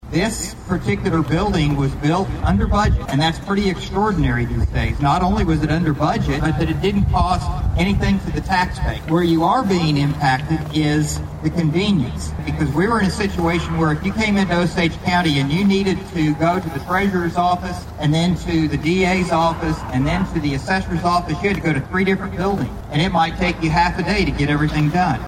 Osage County District Attorney Mike Fisher was one of several speakers at the ribbon cutting event.